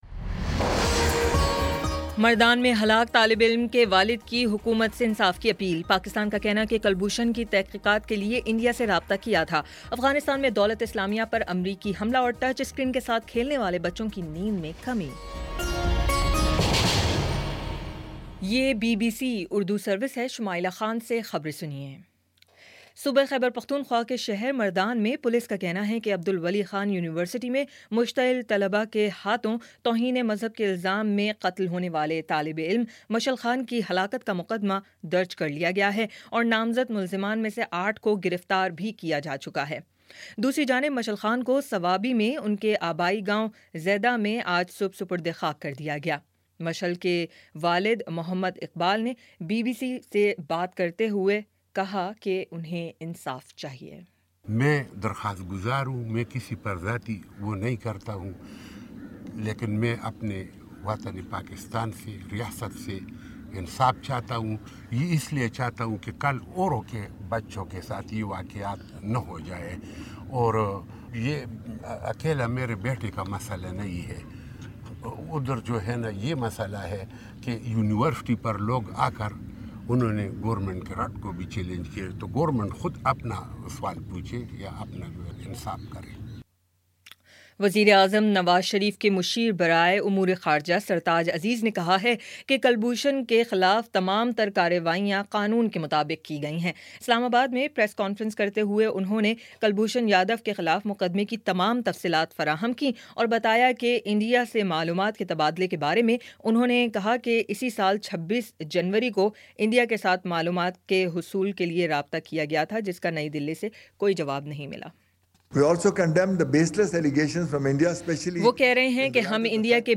اپریل 14 : شام پانچ بجے کا نیوز بُلیٹن